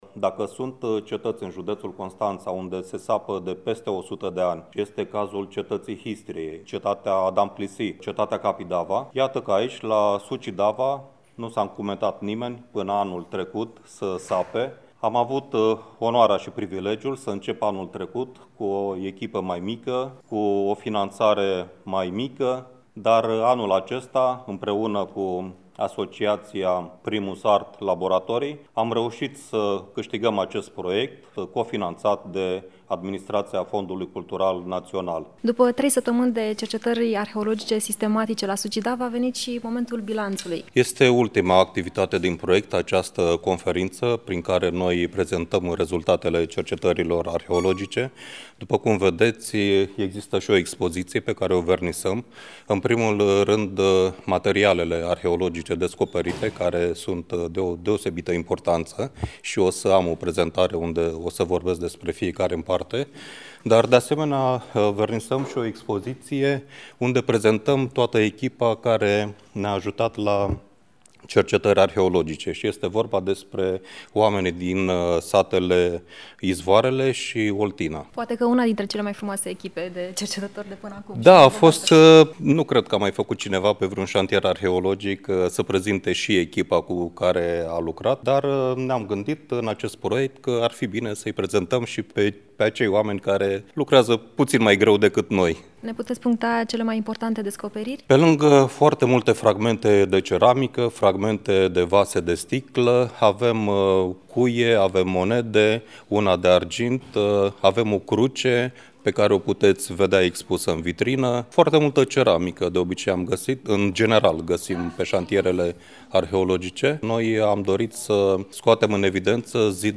Cu toții au făcut o echipă foarte bună, iar la conferința de presă din 24 octombrie au prezentat rezultatele muncii lor.